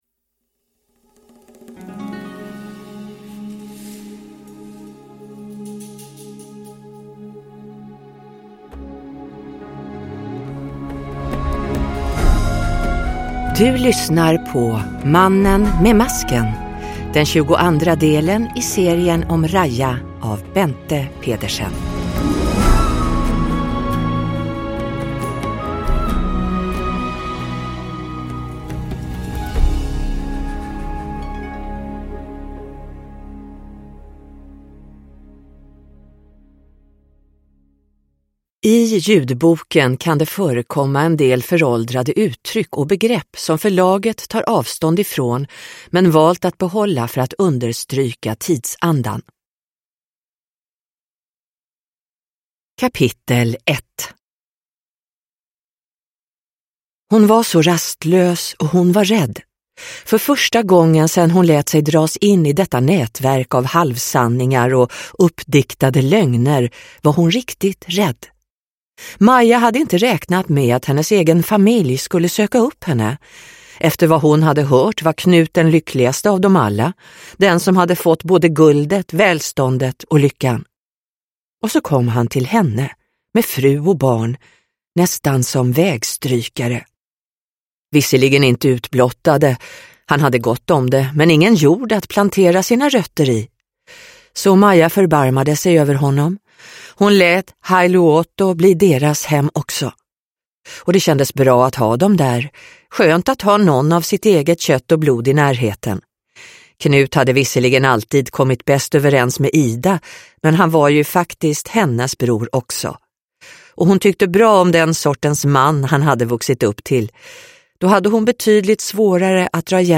Mannen med masken – Ljudbok – Laddas ner